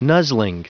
Prononciation du mot : nuzzling